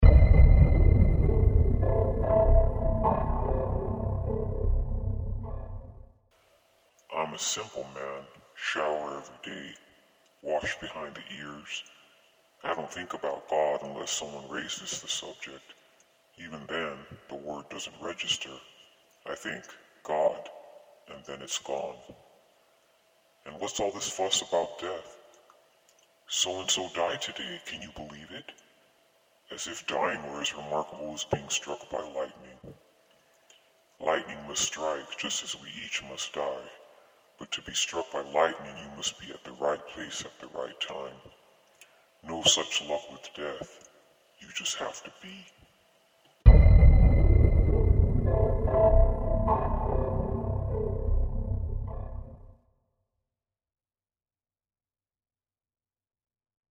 Deep Vocals